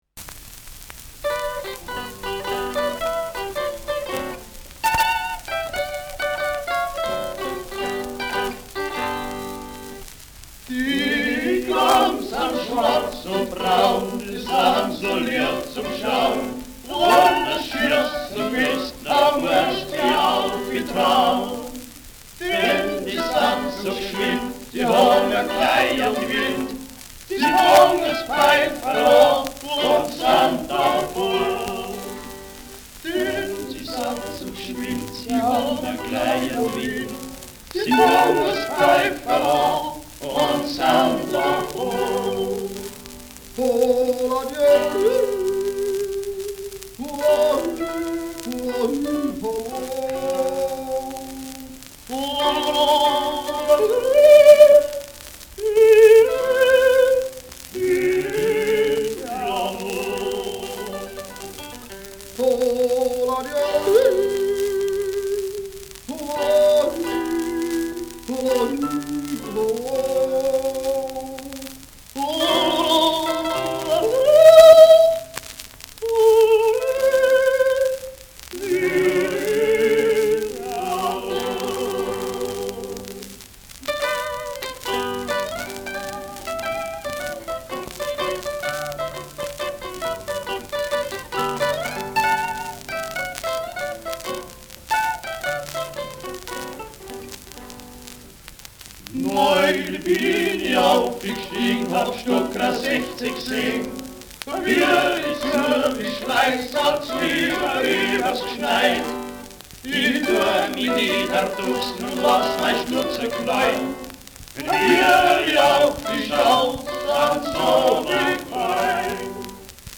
Schellackplatte
Tonrille: einige graue Rillen
leichtes Rauschen : leichtes Knistern
Jodlergruppe Allgäu, Immenstadt (Interpretation)
[Berlin] (Aufnahmeort)